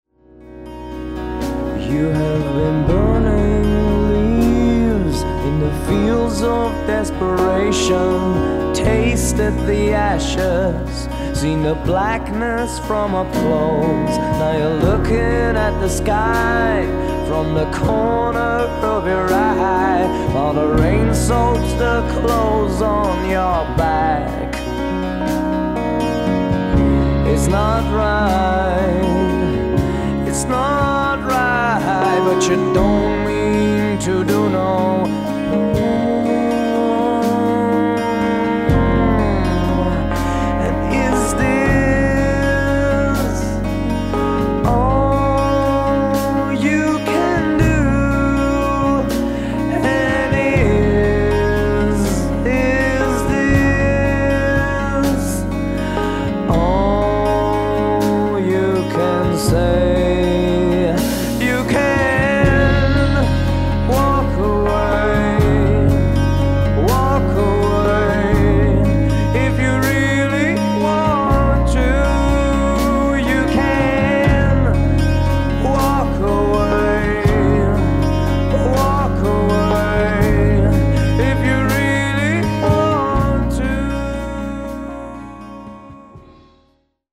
NO BULLSHIT ROCK & ROLL!